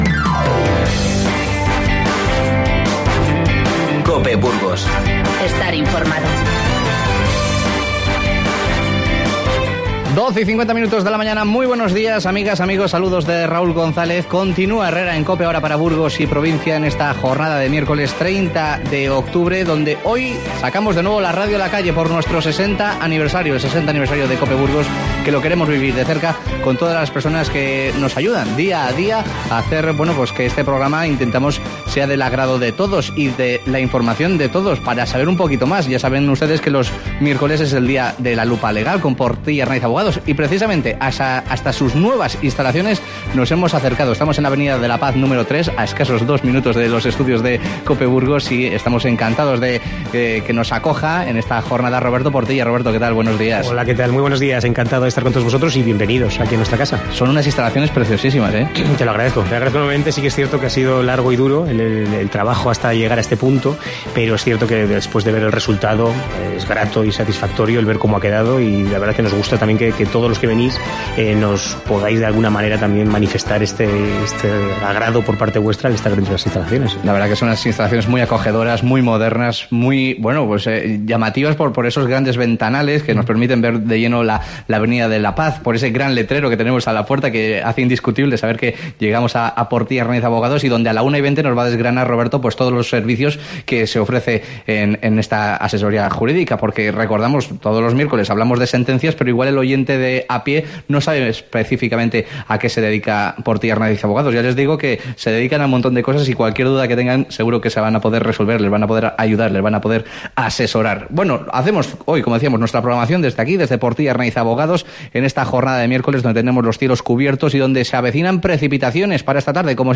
AUDIO: Hoy hacemos nuestra programación local desde Portilla Arnáiz Abogados. Y como miércoles que es, analizamos un nuevo caso en La Lupa Legal.